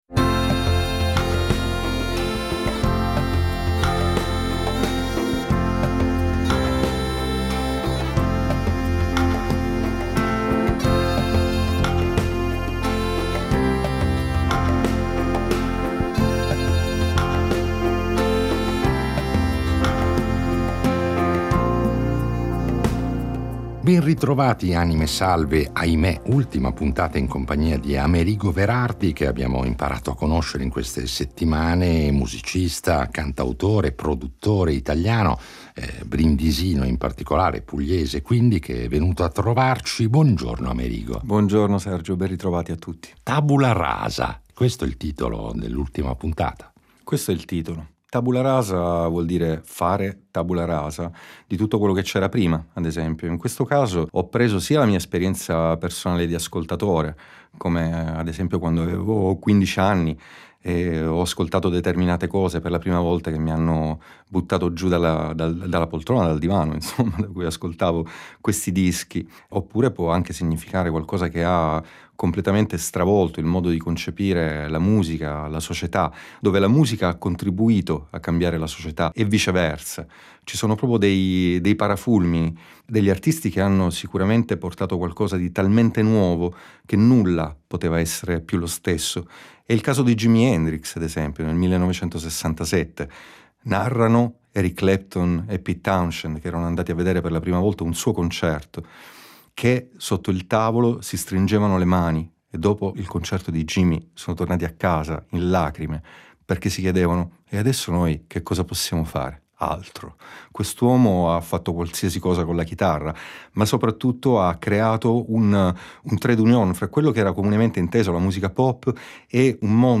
Un ospite prezioso che, come sempre ad “ Anime Salve ”, è venuto a trovarci con la sua chitarra per impreziosire i nostri itinerari sonori con interventi “live”.